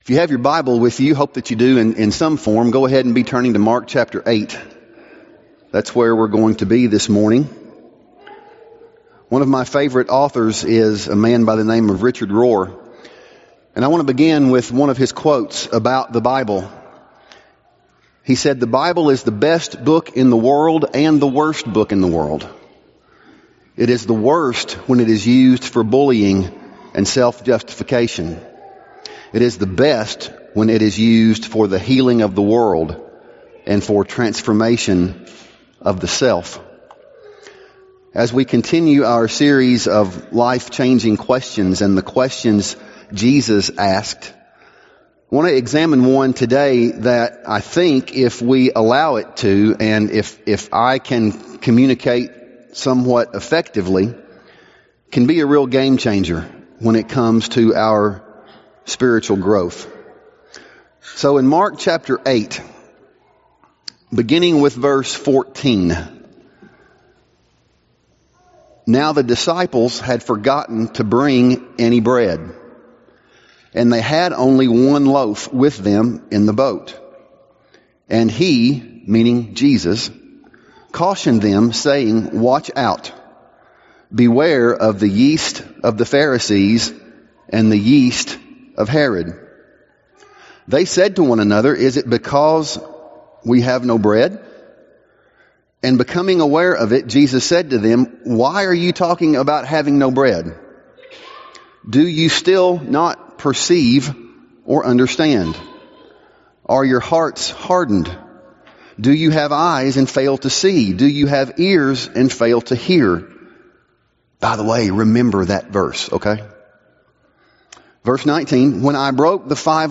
Sermons | University Church of Christ